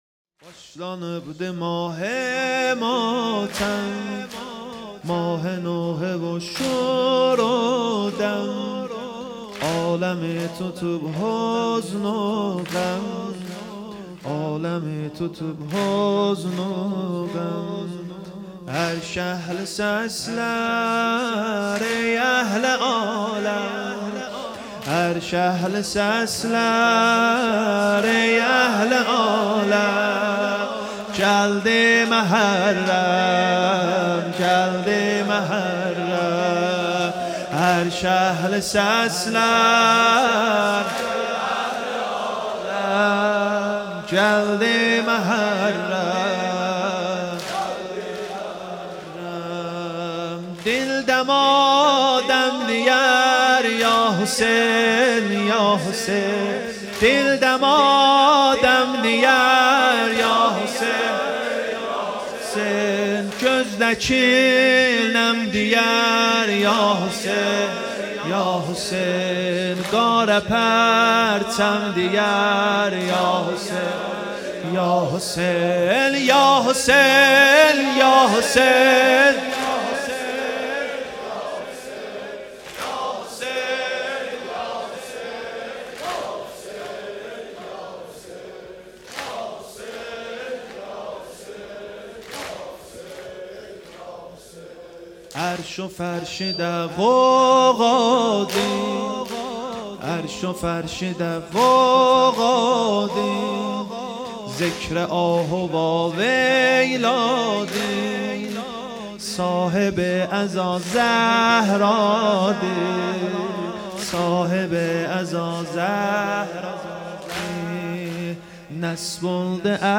سینه زنی واحد 📽 شب اول محرم ۱۴۴۵
حسینیه ام الشهدا